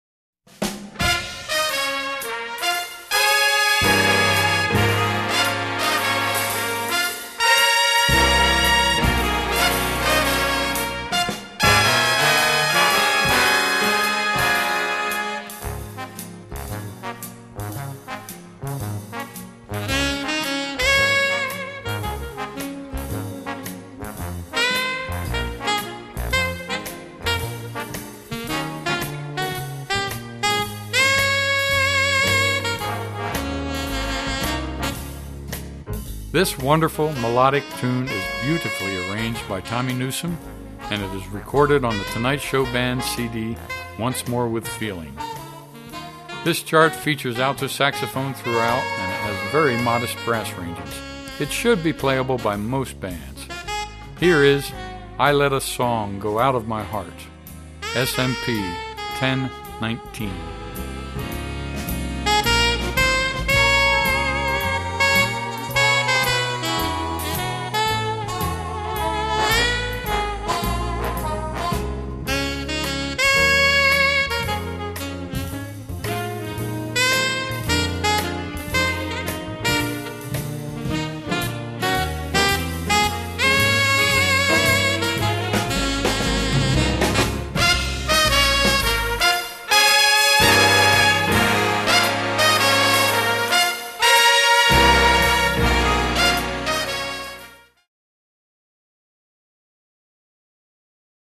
Medium Swing, Alto Sax feature
Voicing: Jazz Ensemble